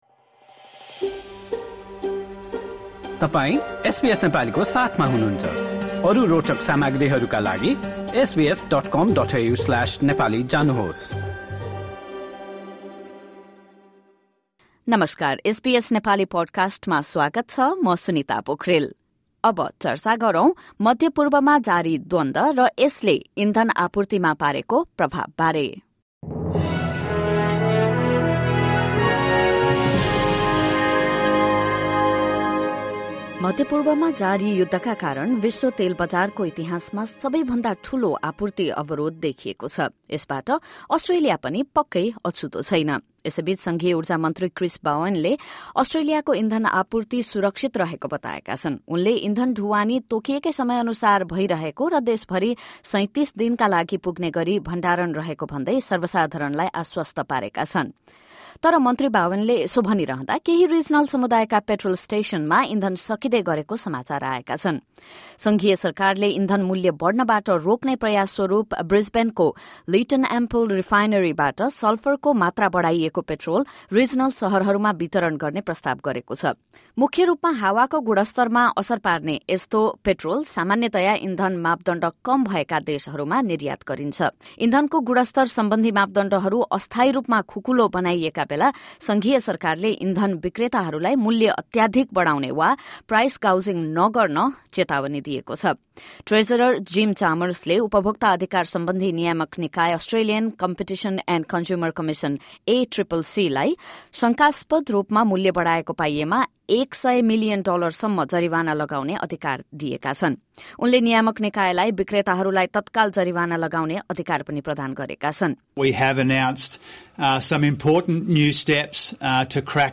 Amid tensions in the Middle East due to the conflict between the US-Israel, and Iran, the global oil market has been affected. As concerns grow worldwide over fuel supply and rising prices, let’s hear in this report what the Australian government is doing and what Nepali speakers living in Australia say about the impact of increasing petrol prices on their daily lives.